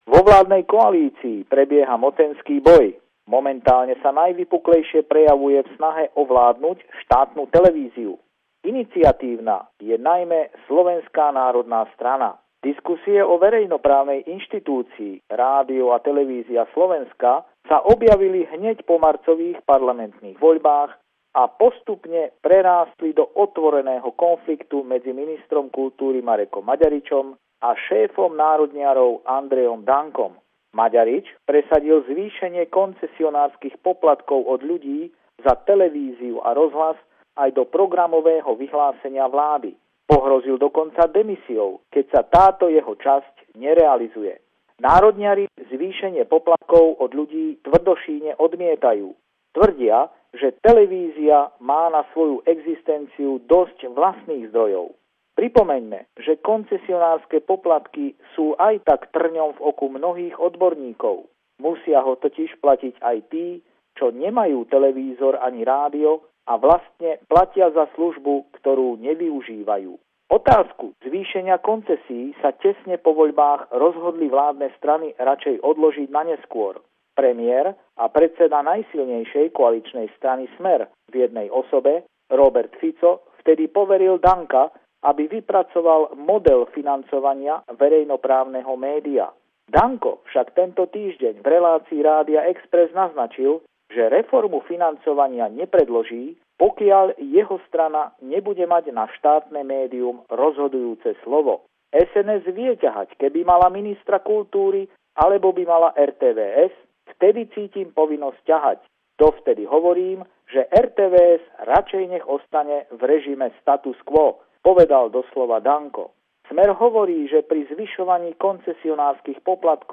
Pravidelný telefonát týždňa z Bratislavy